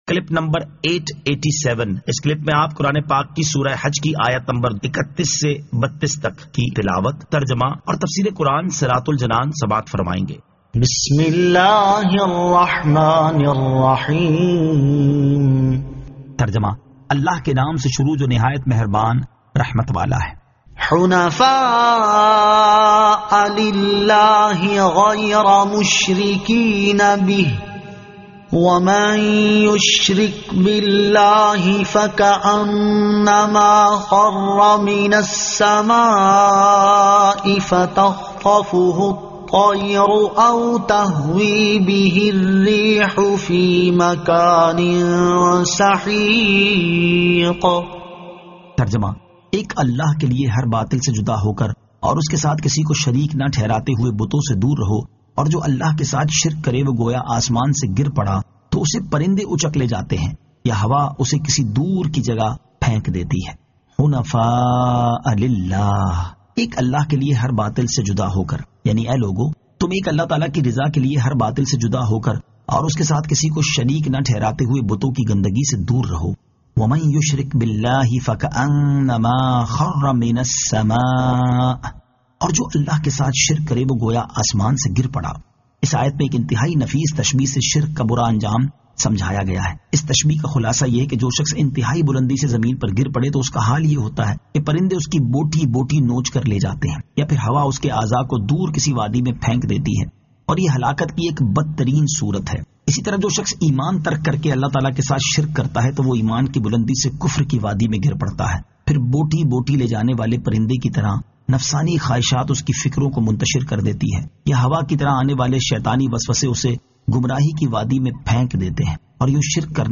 Surah Al-Hajj 31 To 32 Tilawat , Tarjama , Tafseer